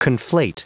Prononciation du mot conflate en anglais (fichier audio)
Prononciation du mot : conflate